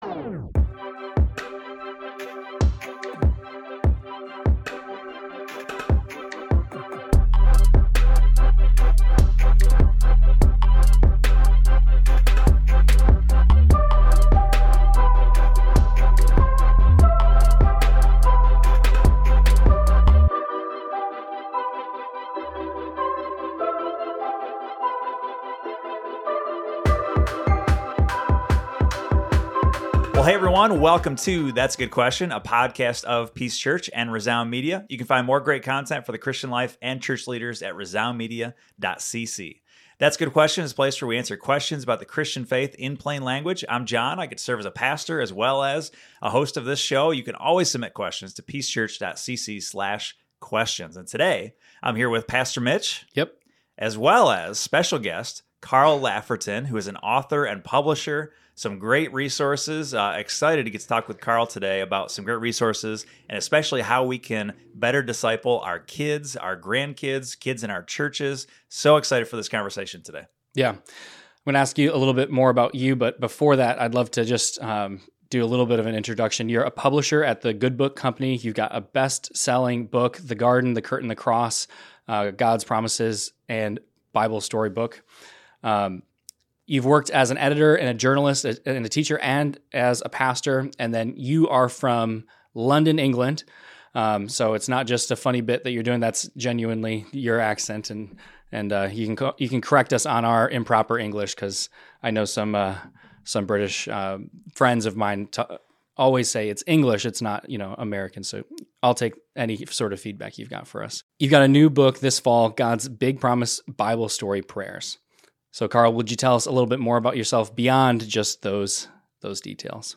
Discipling Our Children: A Conversation